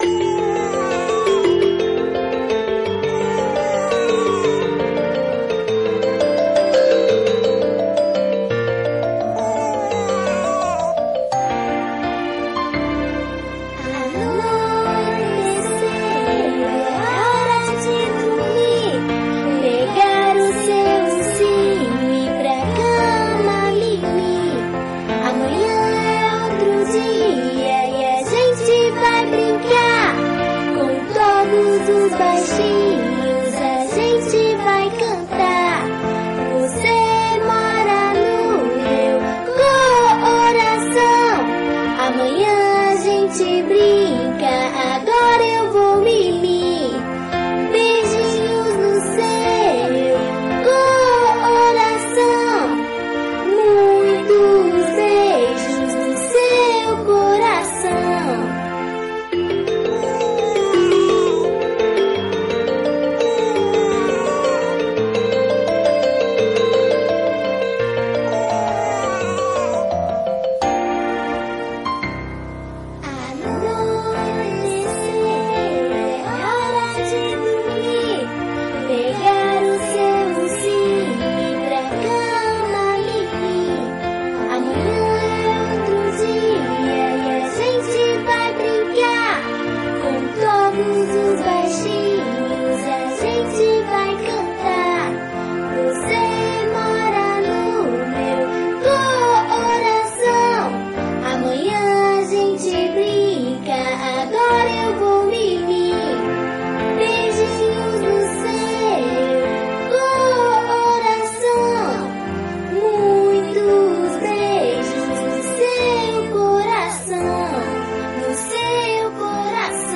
EstiloInfantil